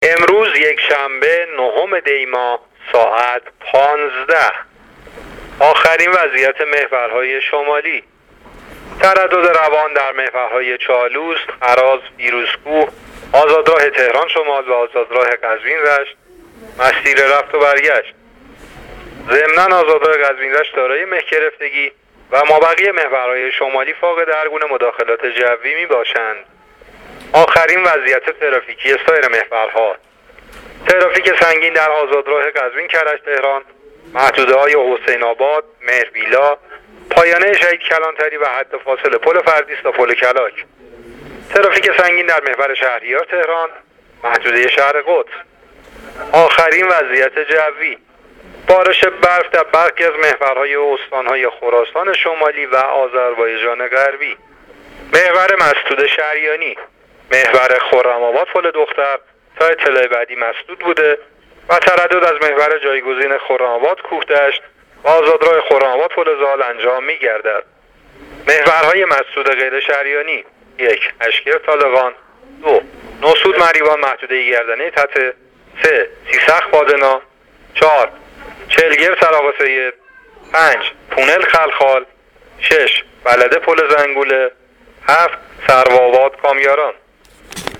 گزارش رادیو اینترنتی از آخرین وضعیت ترافیکی جاده‌ها تا ساعت ۱۵ نهم دی؛